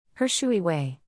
full-name-pronunciation.mp3